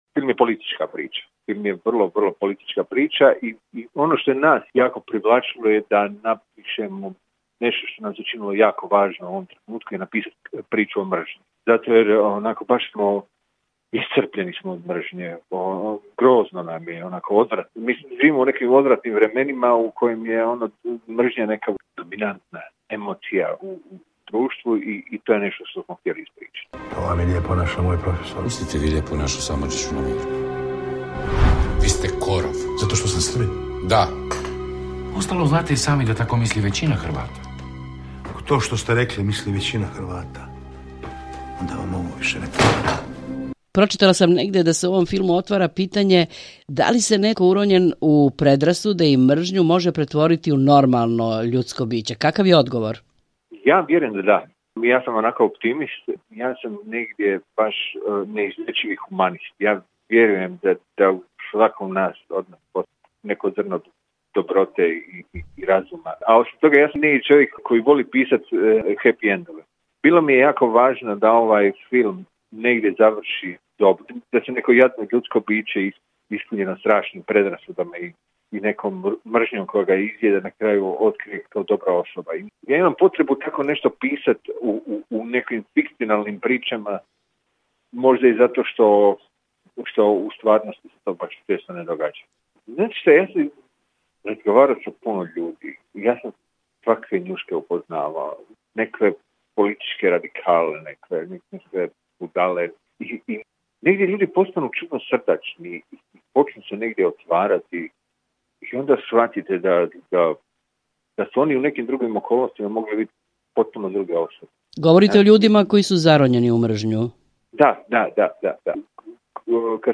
U Intervjuu nedelje hrvatski pisac i scenarista Ante Tomić govori o ustaštvu u savremenoj Hrvatskoj, a povod je film Rajka Grlića "Ustav Republike Hrvatske".